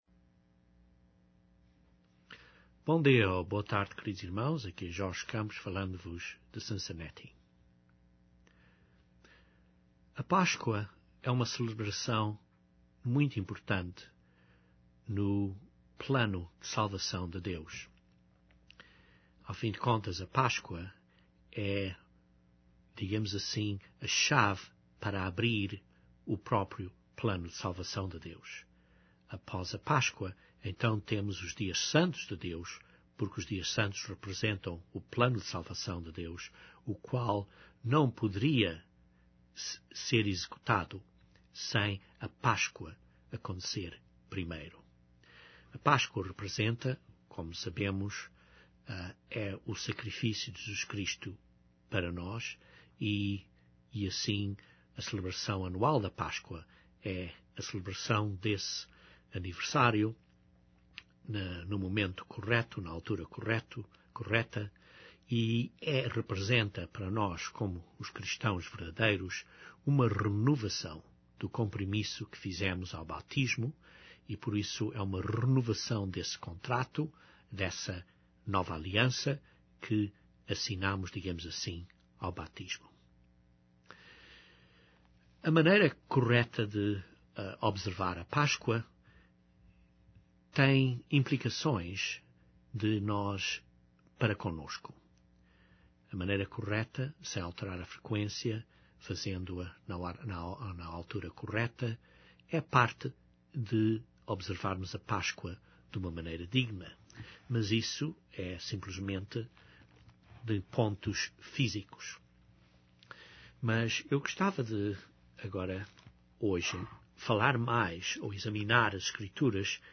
Este sermão examina as escrituras para vermos a necessidade de nos prepararmos para a Páscoa, para a observar duma maneira digna. O sermão extrai da Bíblia alguns pontos importantes para nos prepararmos para a Páscoa.